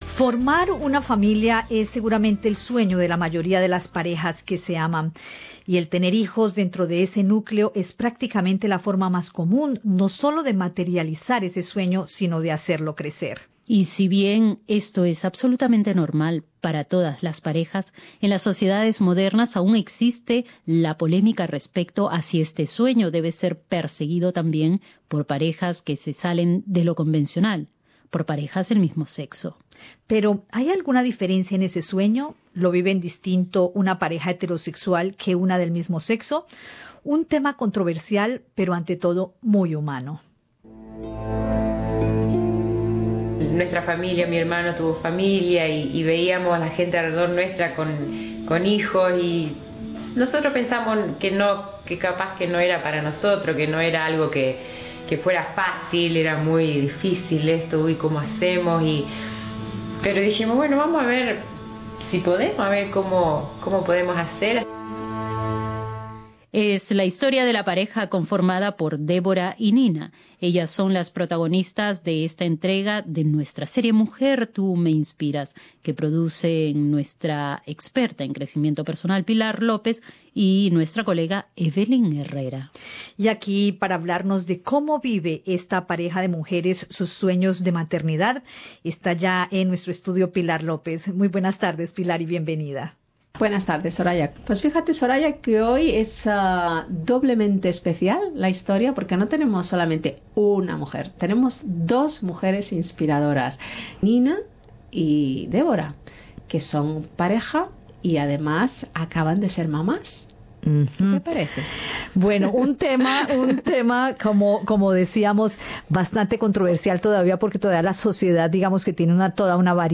Escucha el podcast con la entrevista